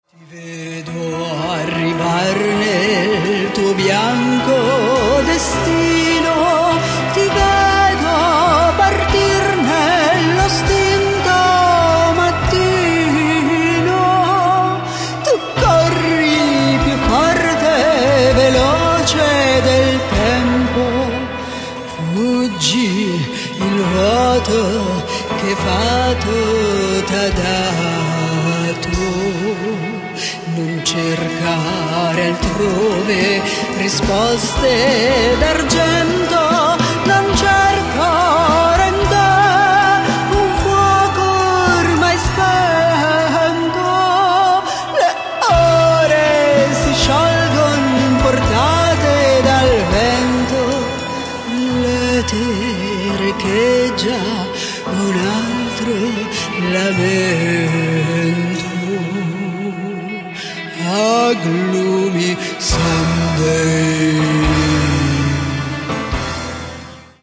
デカダン漂うゴシック＋キャバレー音楽が展開
vocals
guitars, bass-guitar
piano, keyboards
drums, cajon, cymbals, drumpads